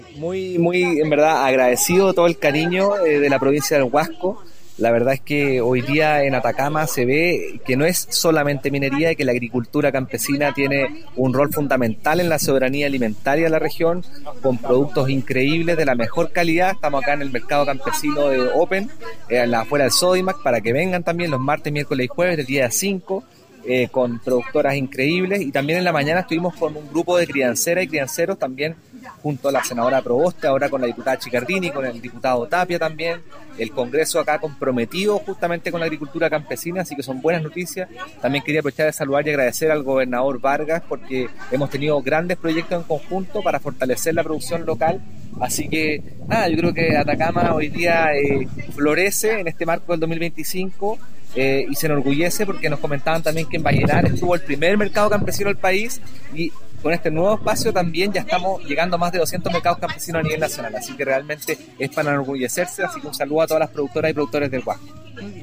La inauguración se llevó a cabo en el marco de la visita del director nacional de INDAP, Santiago Rojas, quien destacó la relevancia de la agricultura campesina en la soberanía alimentaria.
cuna-director-nacional-indap-santiago-rojas.mp3